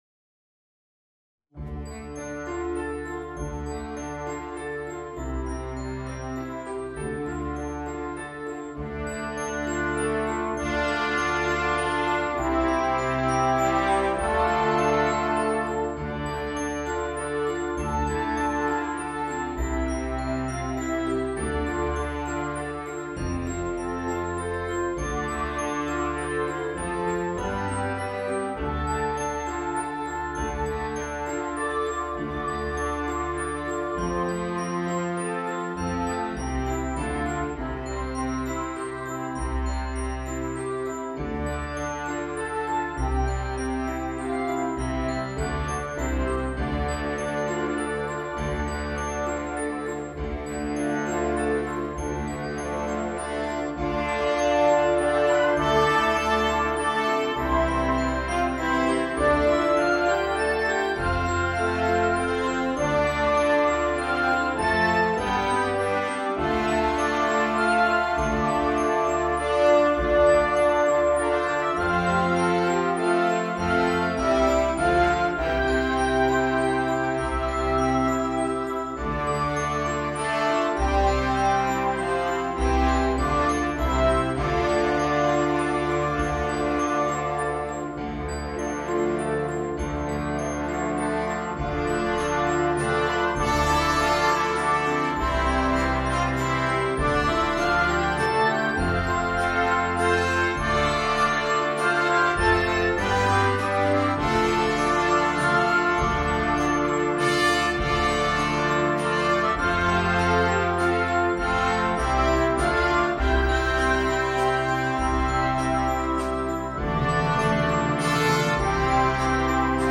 The MP3 was recorded with NotePerformer 3
Folk and World